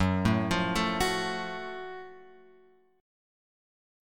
F#mM7 chord {2 0 3 2 x 2} chord